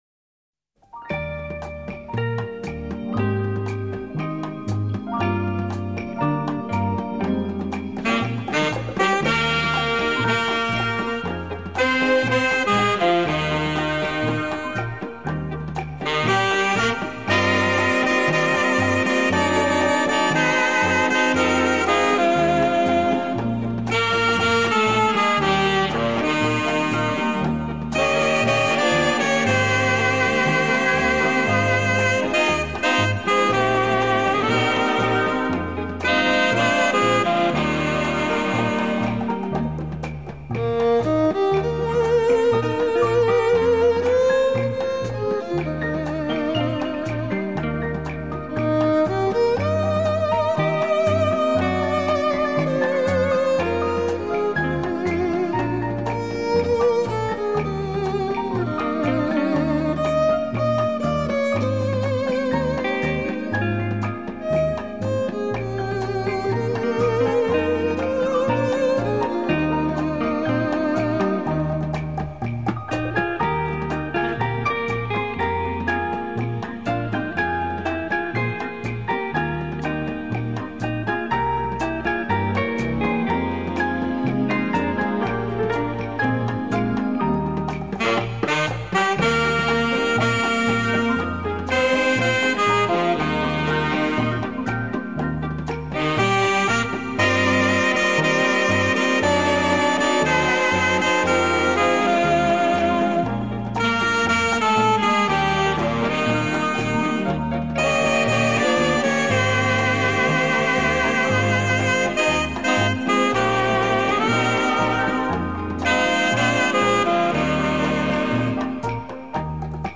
Румба